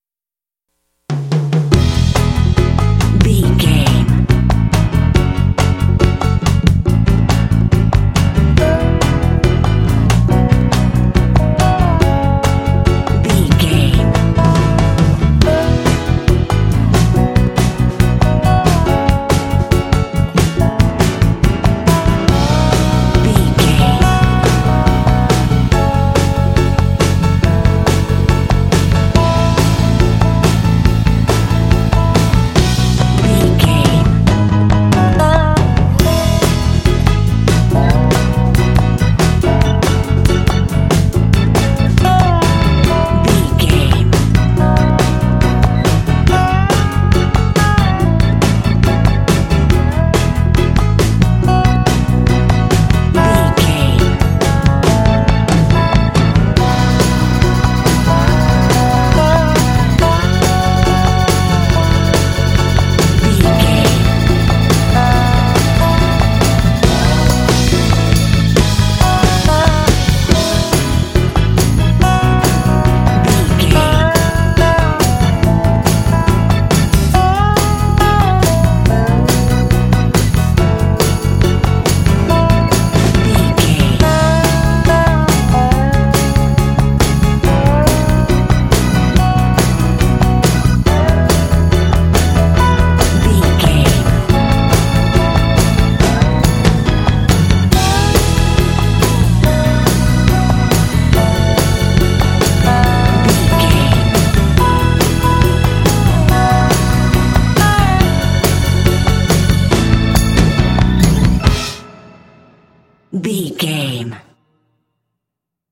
Ionian/Major
lively
playful
joyful
cheerful/happy
optimistic
drums
bass guitar
piano
electric guitar
electric organ
percussion
country rock